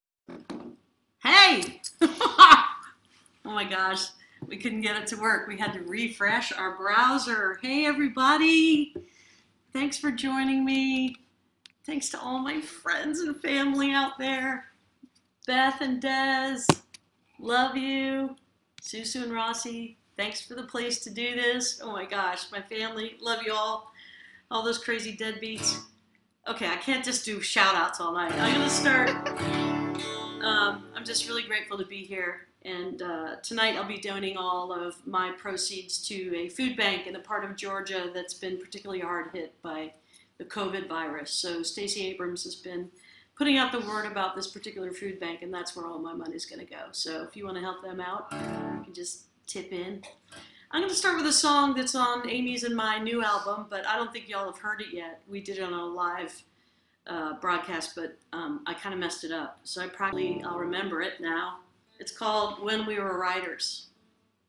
(captured from the live video stream)
02. talking with the crowd (1:04)